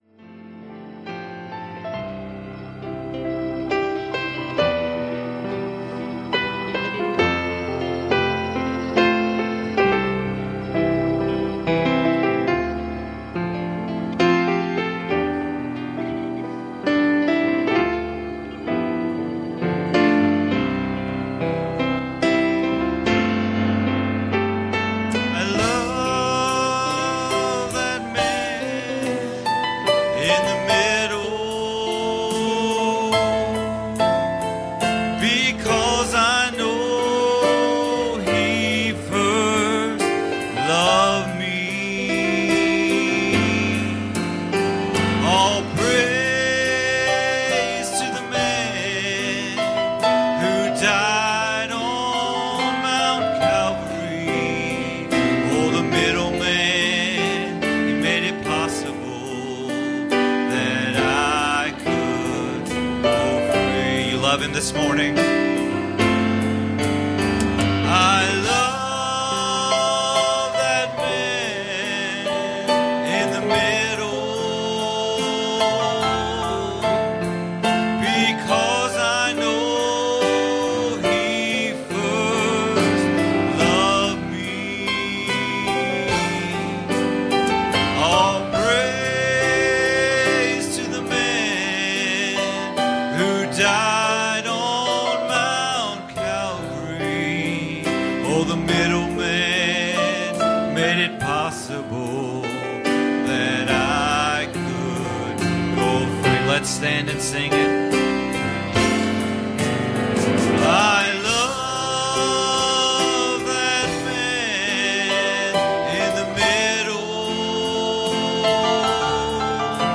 Galatians 1:6 Service Type: Sunday Morning "This is God's Word